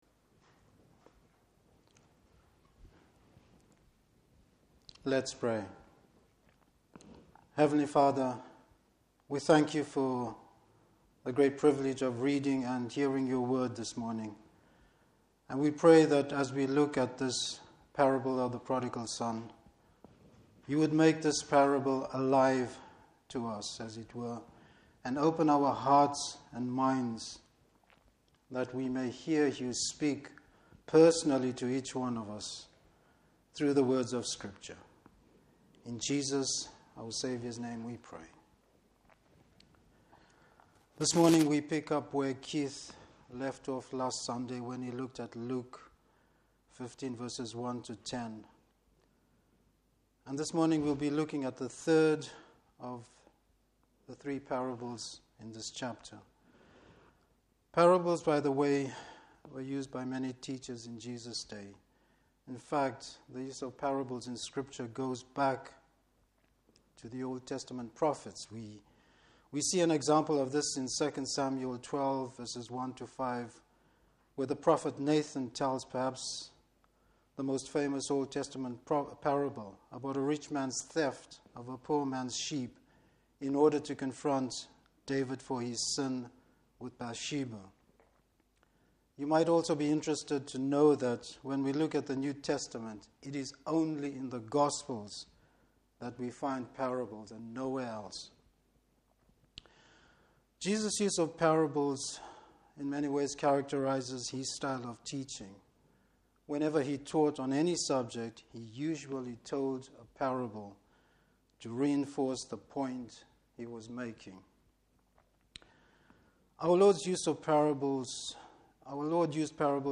Service Type: Morning Service Bible Text: Luke 15:11-24.